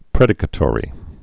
(prĕdĭ-kə-tôrē)